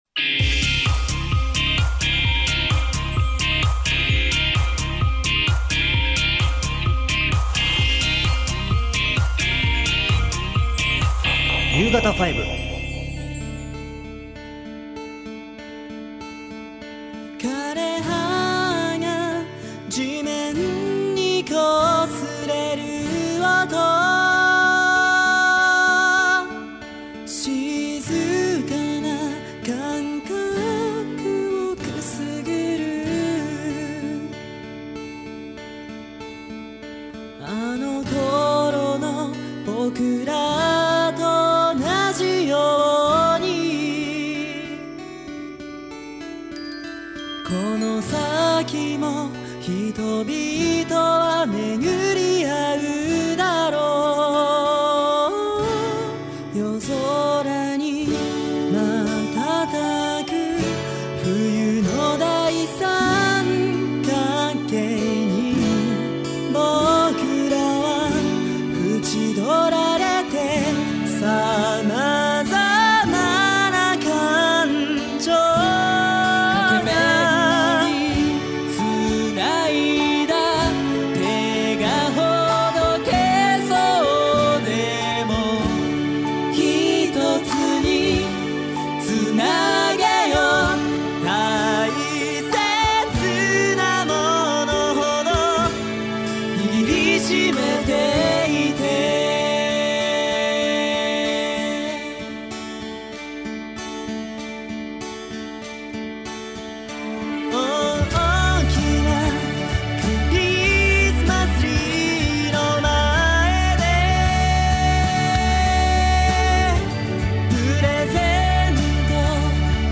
歌声は本当に大人っぽい彼らですが、会って話してみるとやはりフレッシュ感満載！！！